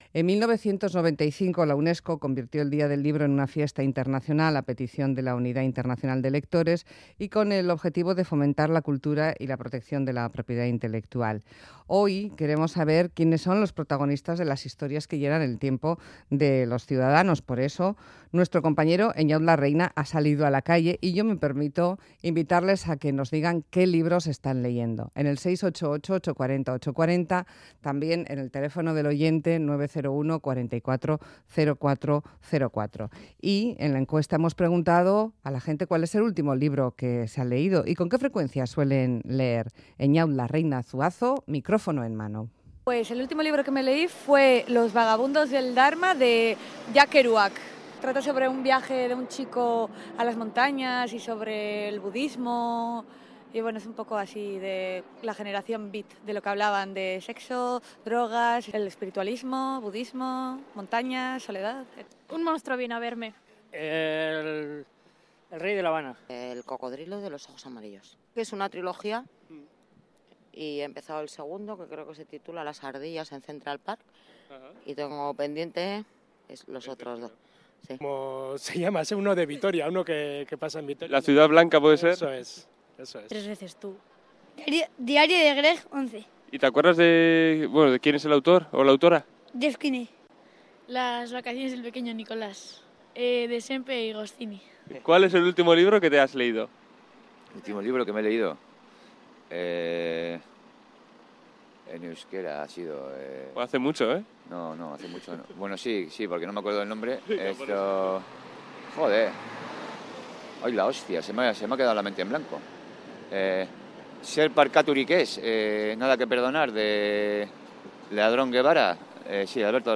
Encuesta del Día Internacional del Libro en Más Que Palabras, Radio Euskadi
Audio: Hemos salido a la calle a recopilar los títulos de los libros que leen nuestro oyentes, un ejercicio de riesgo porque es muy fácil irse por peteneras.